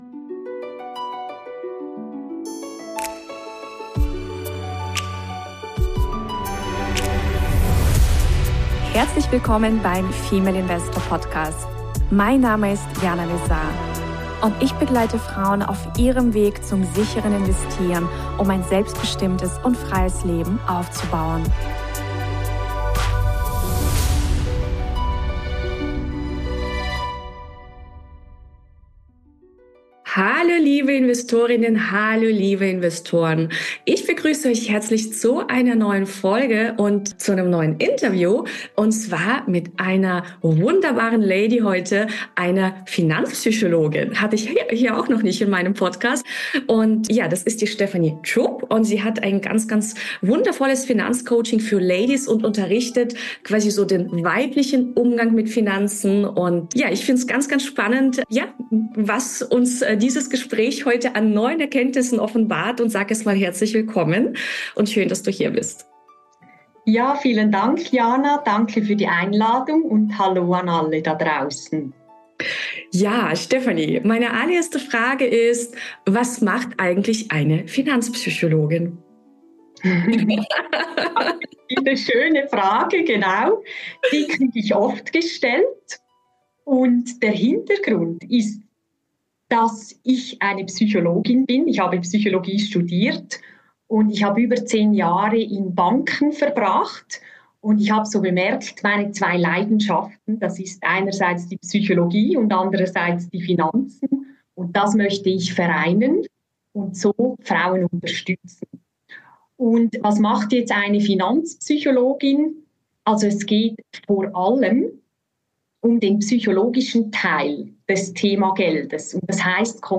#185: Interview mit Finanzpsychologin über das weibliche Geld-Mindset ~ Female Investor Podcast